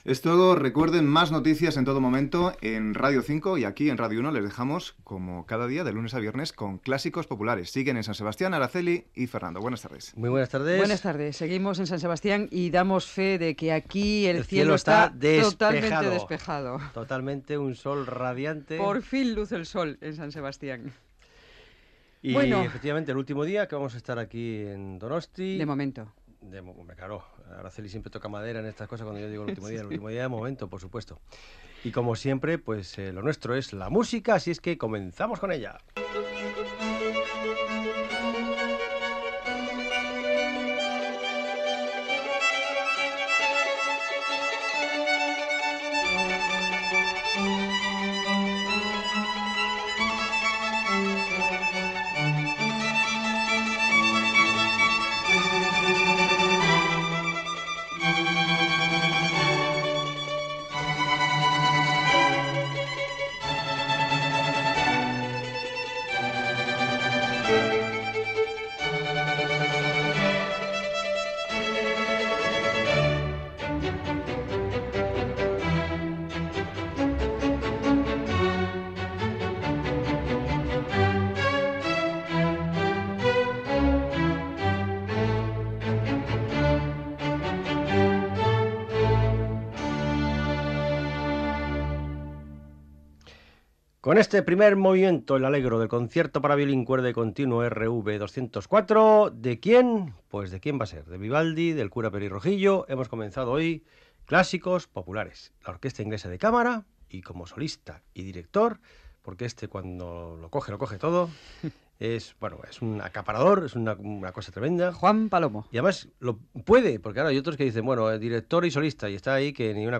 Espai fet des de Donòstia, Comentari sobre el temps, música, comentari del tema escoltat, presentació del següent tema musical, música
Gènere radiofònic Musical